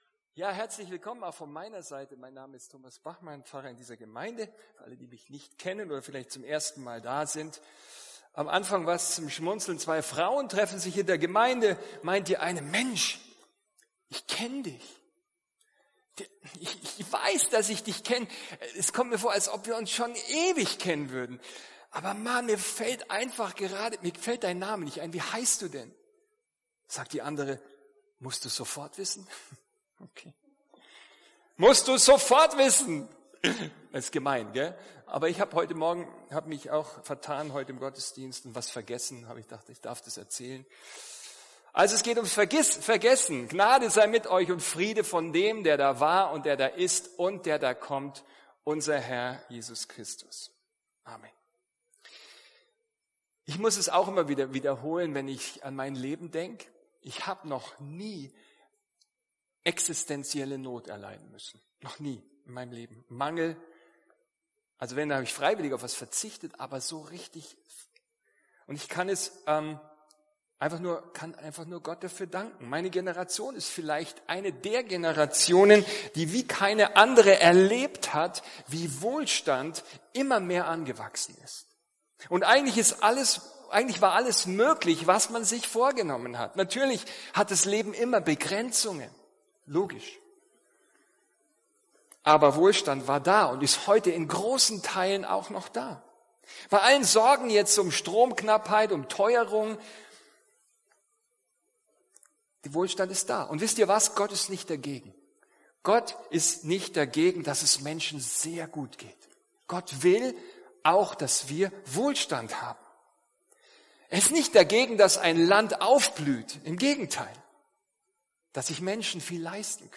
Ein Studienblatt zur Predigt ist im Ordner “Notes” verfügbar